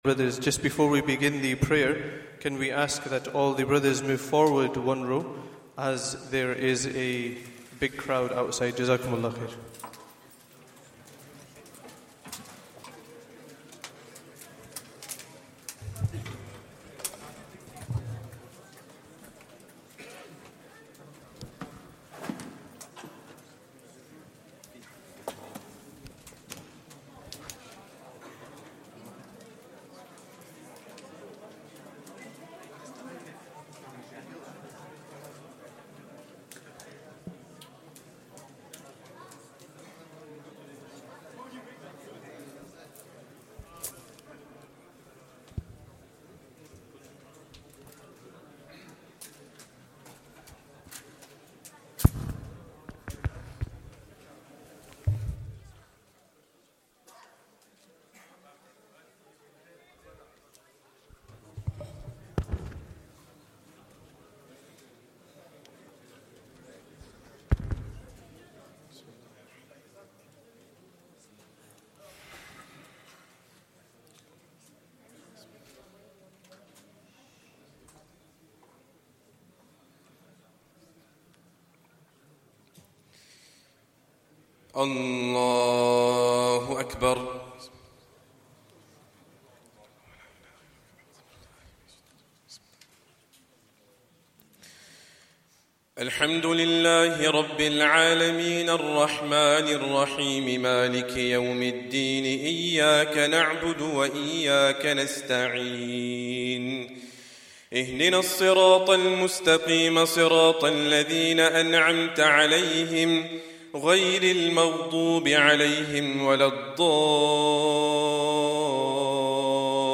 Day 27 - Khatm-ul-Quran Dua - Taraweeh 1444